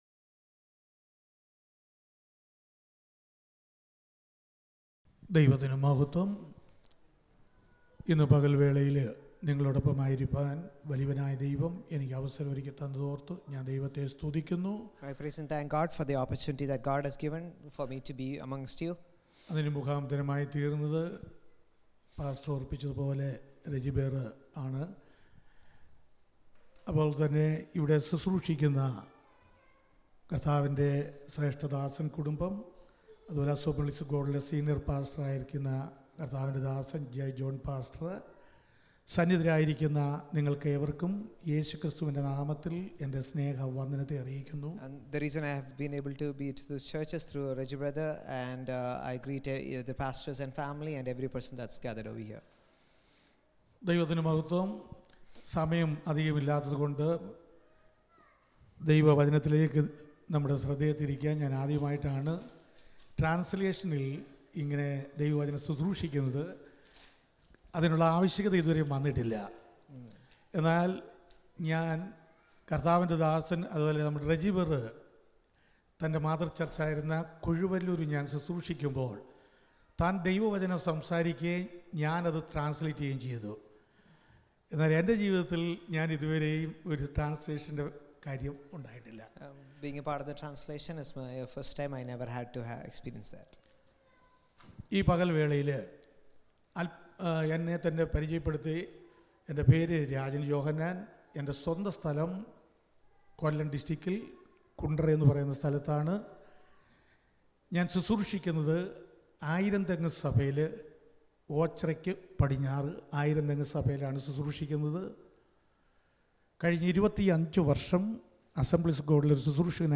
sermon-24-10-6.mp3